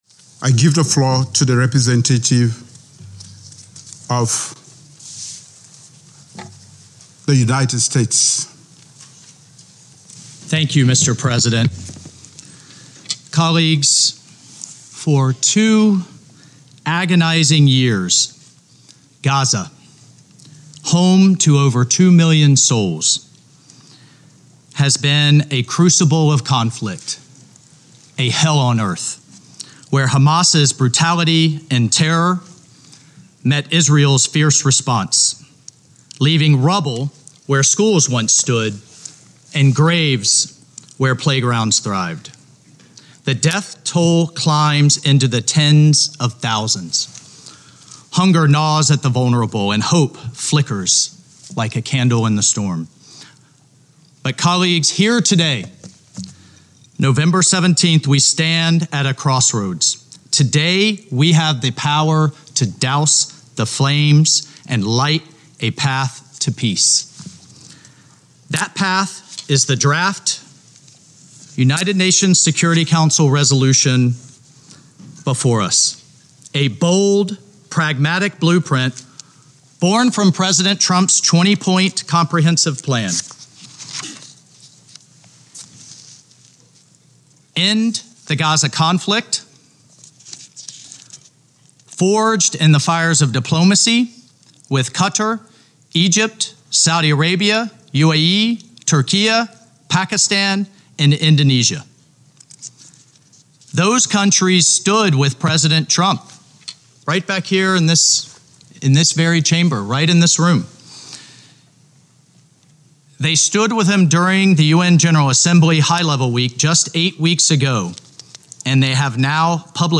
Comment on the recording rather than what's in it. delivered 17 November, UNHQ, New York, NY Audio Note: AR-XE = American Rhetoric Extreme Enhancement